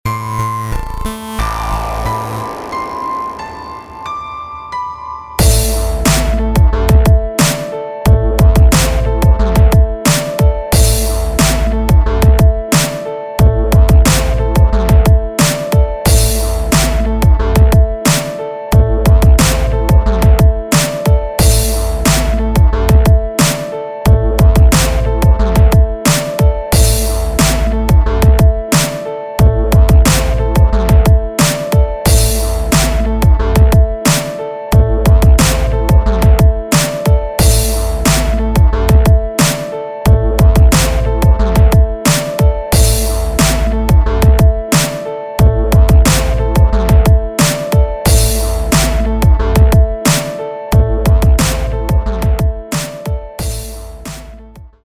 • Качество: 320, Stereo
Хип-хоп
без слов
красивая мелодия
качающие
experimental
beats
Piano
Классный бит, качает!